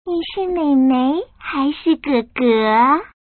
Index of /mahjong_paohuzi_Common_test1/update/1577/res/sfx_pdk/woman/